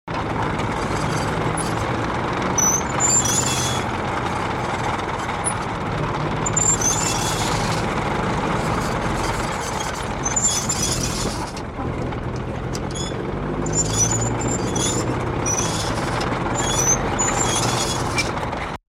دانلود آهنگ تانک 6 از افکت صوتی حمل و نقل
جلوه های صوتی
دانلود صدای تانک 6 از ساعد نیوز با لینک مستقیم و کیفیت بالا